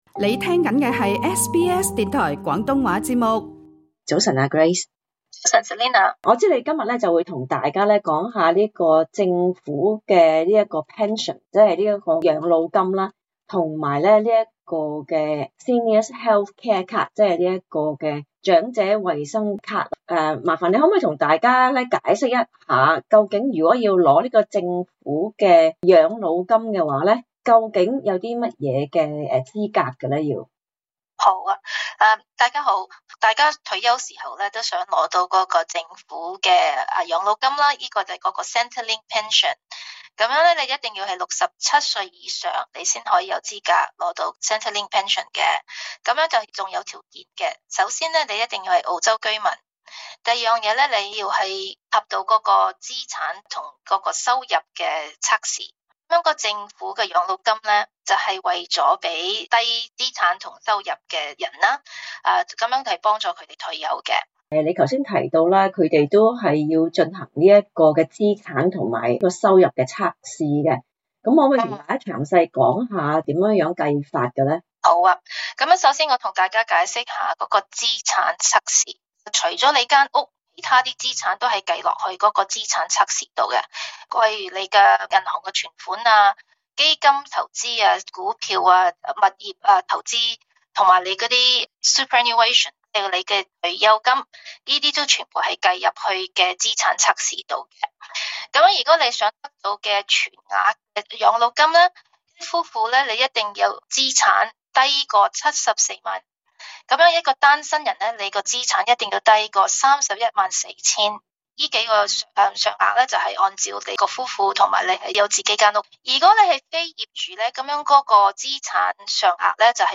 究竟是甚麼福利？大家請收聽這節【退休專輯】有關政府養老金的訪問。